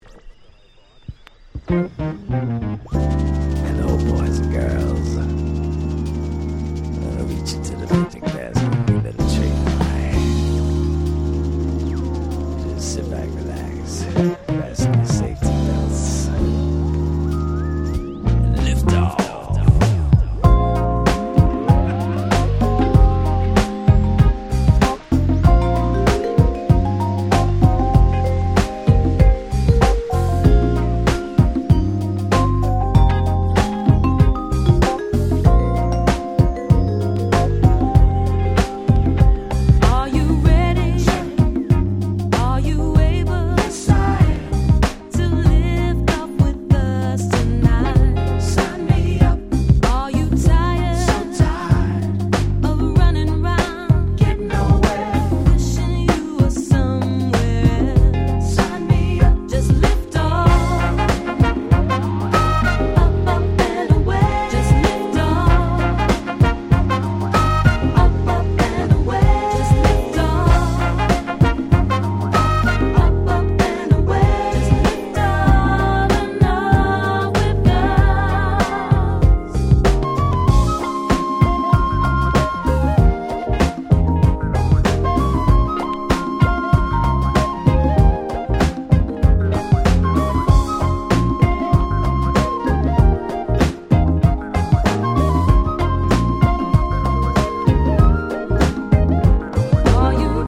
Saxの音が気持ちの良い大変SmoothなUK Soulです！
アシッドジャズ　R&B